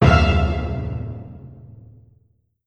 effect__stinger_1.wav